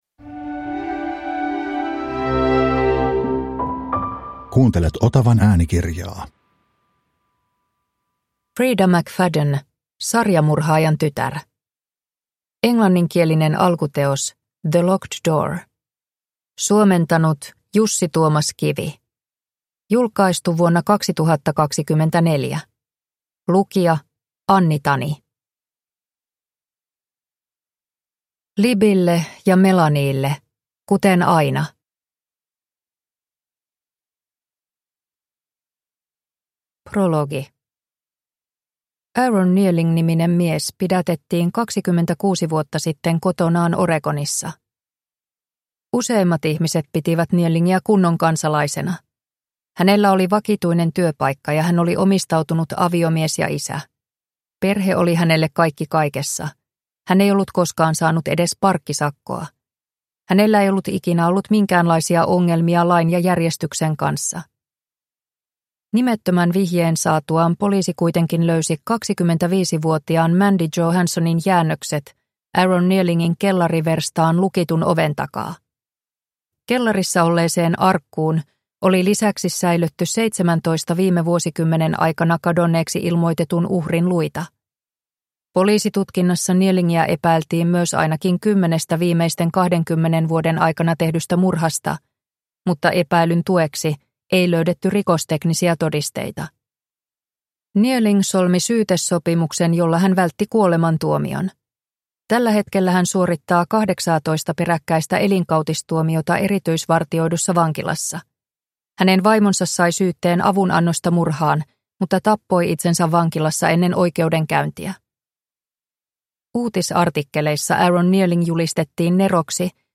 Sarjamurhaajan tytär – Ljudbok